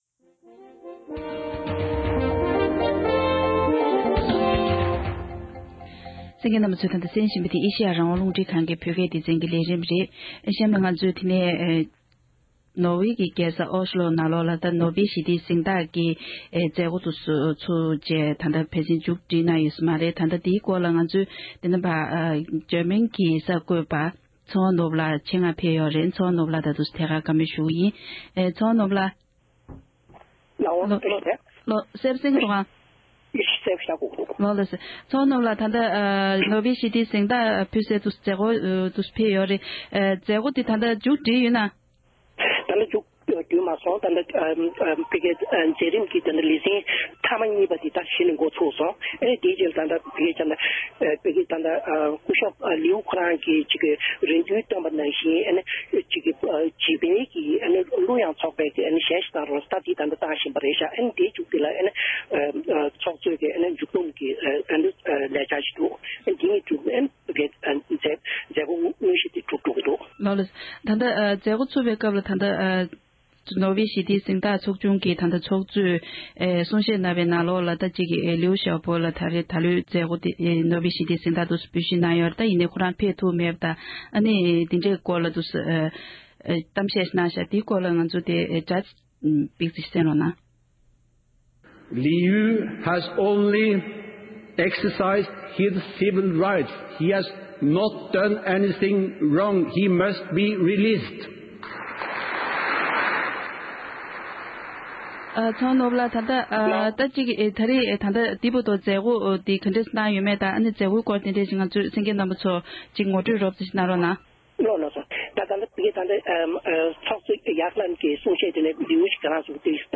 ས་གནས་ས་ཐོག་ནས་ཐད་ཀར་ངོ་སྤྲོད་གནང་བར་གསན་རོགས༎